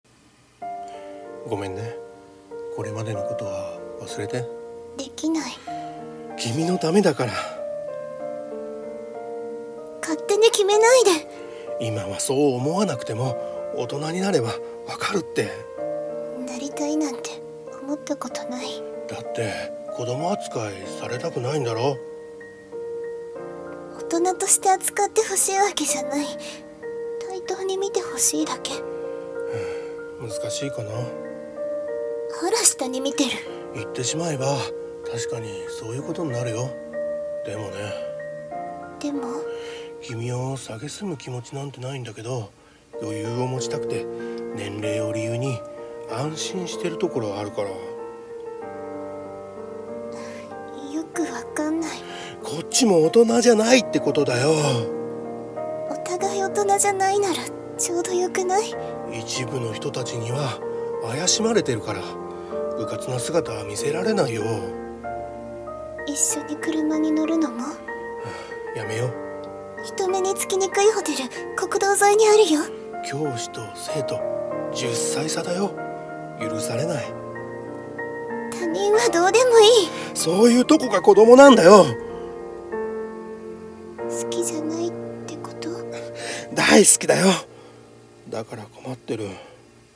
好きが、許されない。【二人声劇】